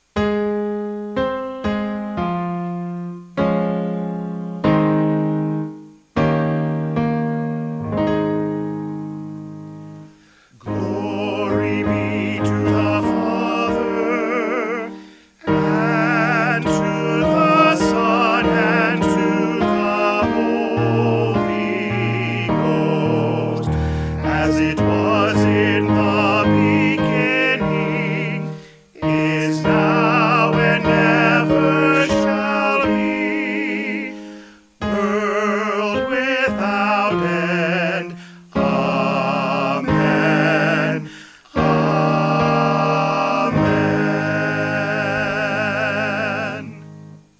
4 Part - MP3 (Listen)